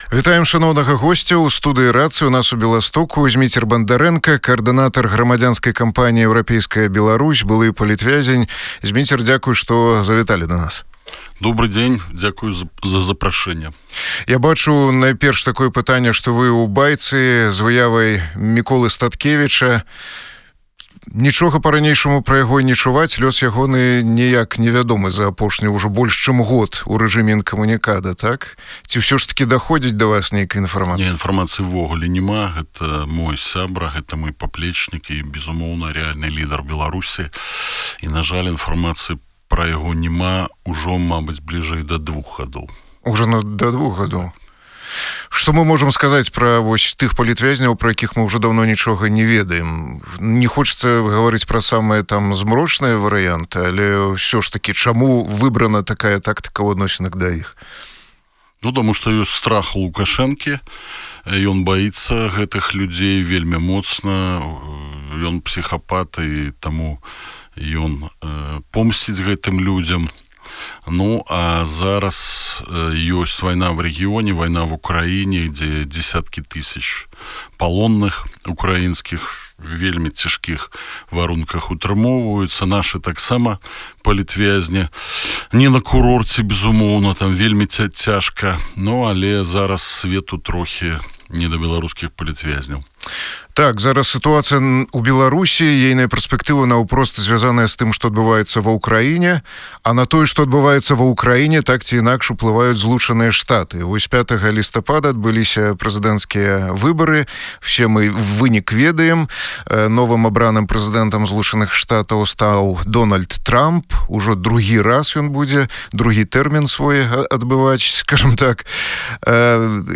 Европейцы могут закончить войну быстрее Трампа Інтэрв'ю 13.11.2024 23:35 No comments Республиканцы будут жестче противостоять диктаторским режимам